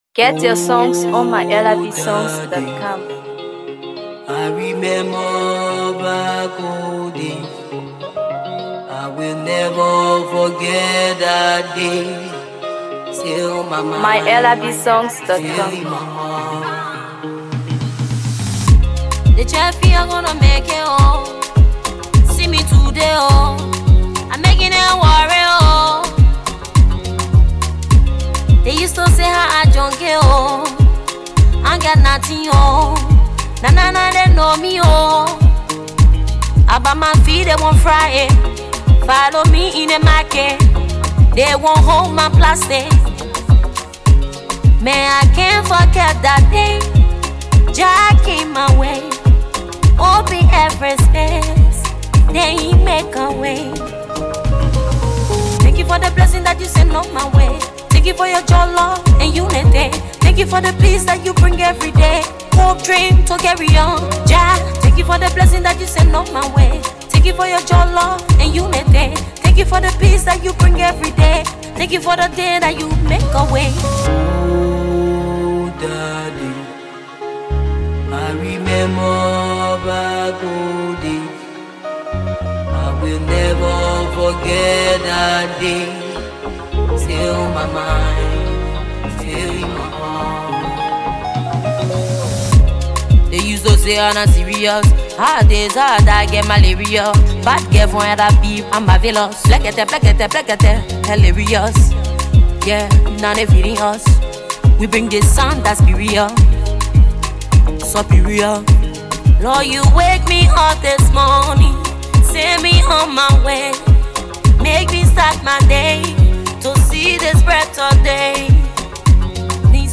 Afro PopMusic
Blending Afro-soul rhythms with heartfelt storytelling